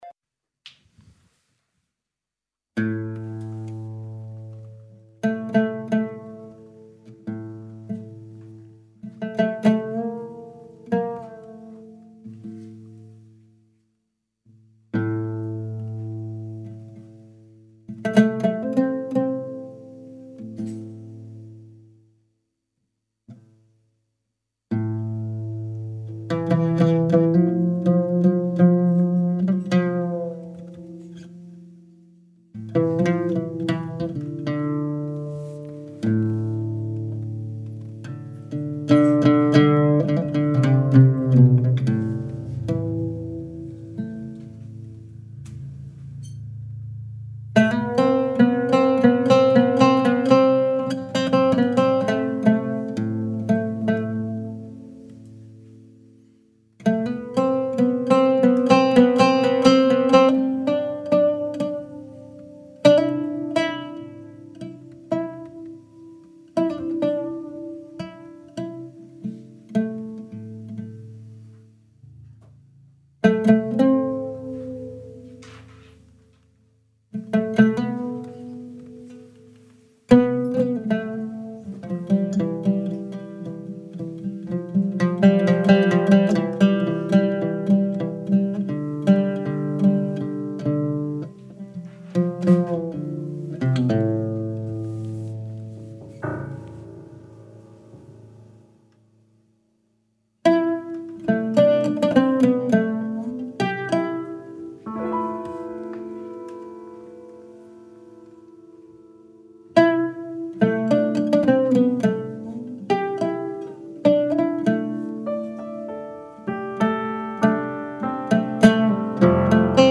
il pianista e compositore
oud